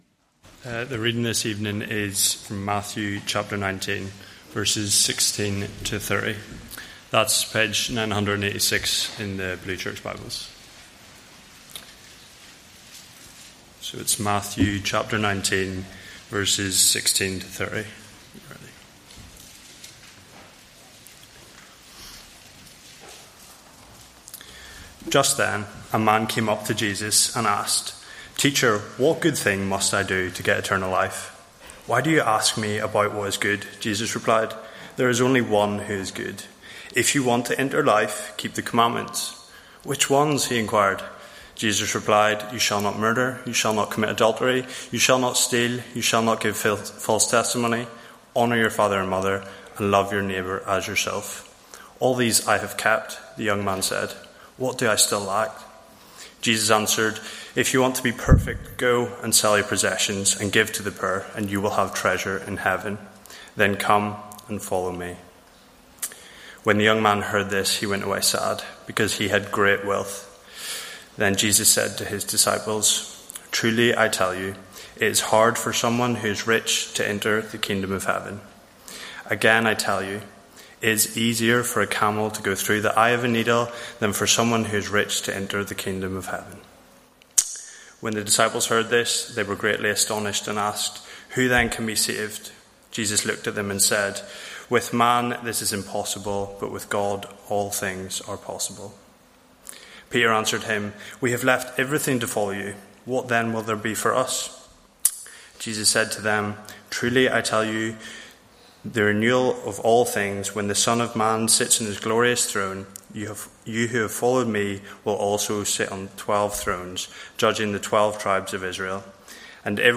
Matthew 19:16-30; 17 November 2024, Evening Service. Sermon Series